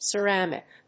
音節ce・ram・ic 発音記号・読み方
/sərˈæmɪk(米国英語), səˈɹæmɪk(英国英語)/